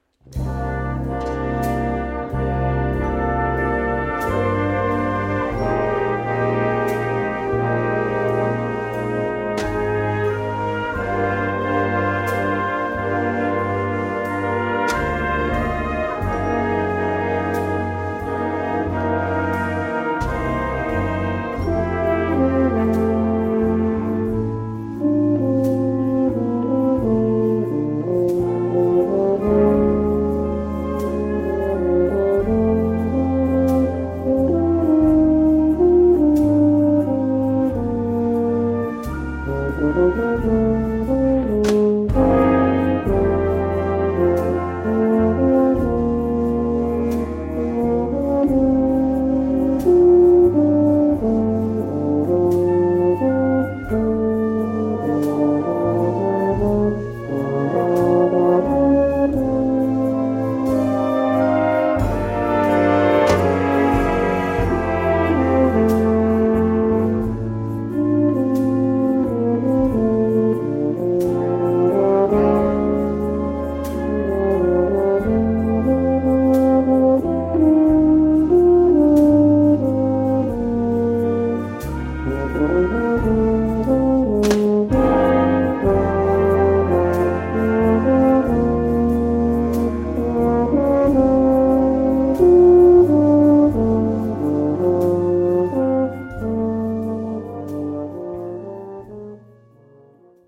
5:37 Minuten Besetzung: Blasorchester PDF